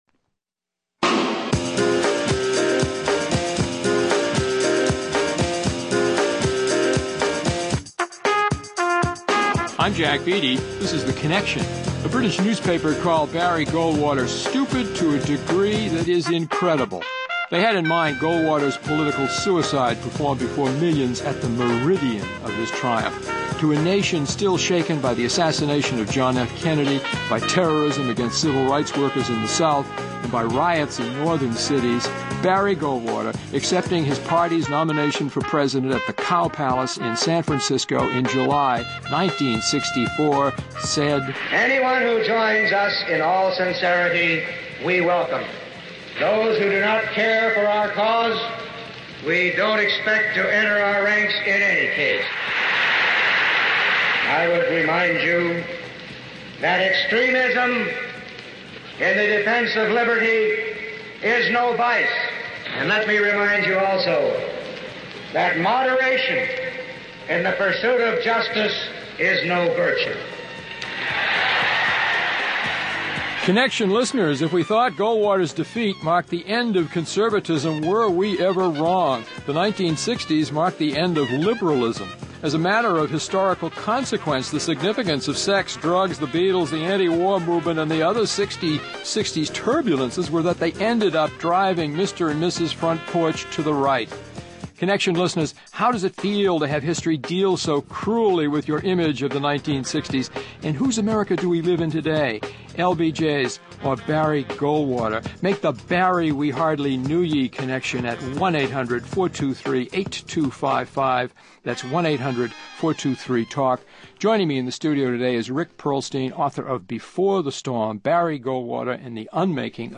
Guests: Rick Perlstein, author of “Before The Storm”; Robert Dallek, professor of history at Boston University and author of the Lyndon B. Johnson biographies “Lone Star Rising” and “Flawed Giant.”